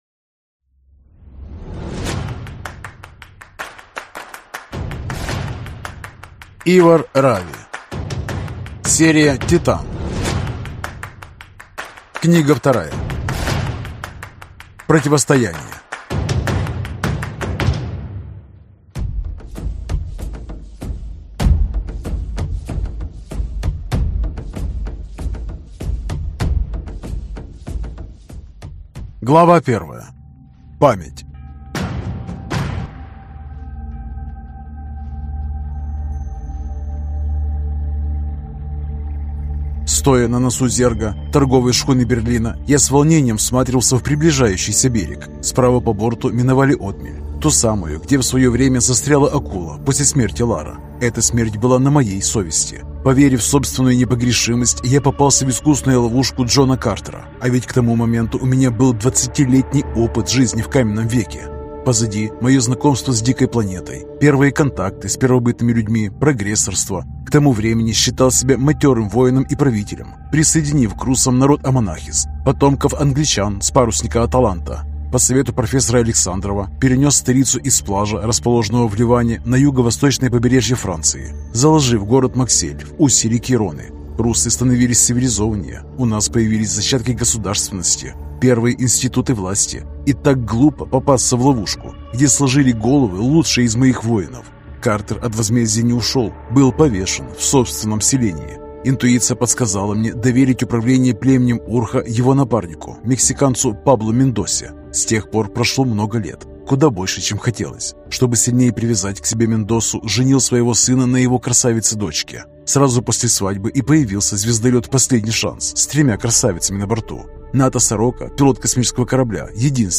Аудиокнига Титан: Противостояние | Библиотека аудиокниг